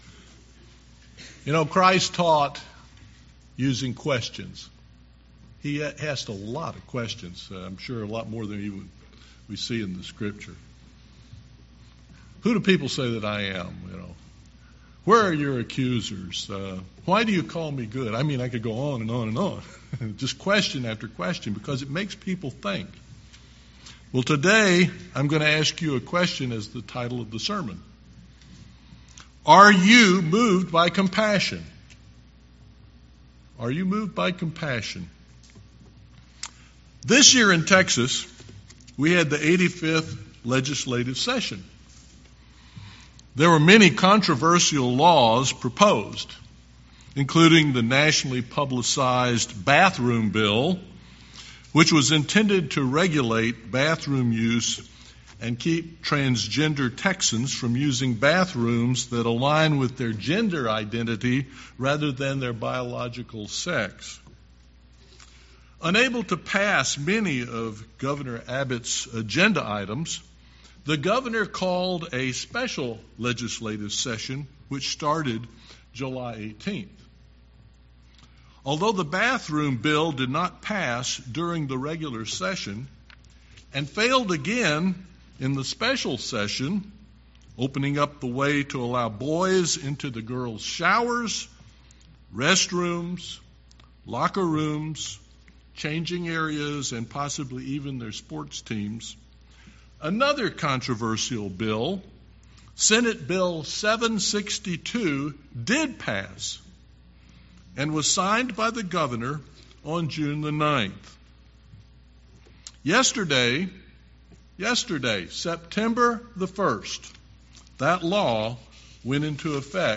Sermons
Given in East Texas